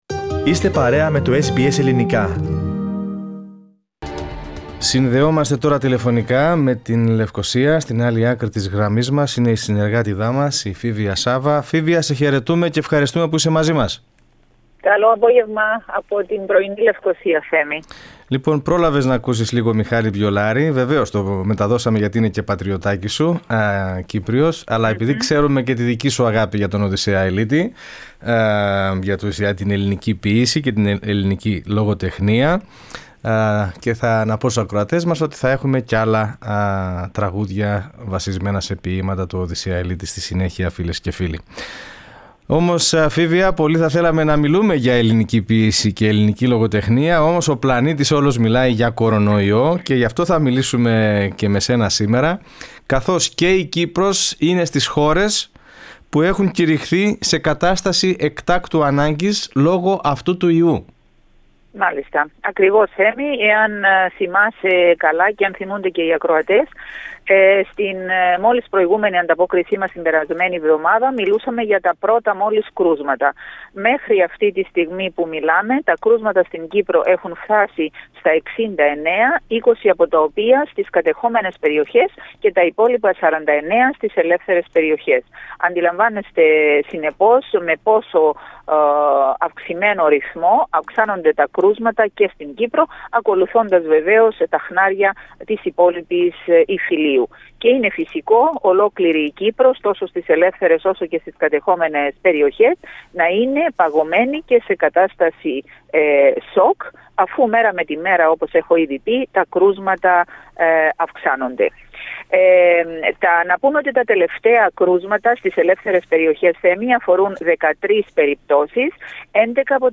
Σε κατάσταση έκτακτης ανάγκης βρίσκεται πλέον η χώρα, με την ανταποκρίτριά μας από τη Λευκωσία να μας μεταφέρει τις τελευταίες εξελίξεις στο μέτωπο του COVID-19.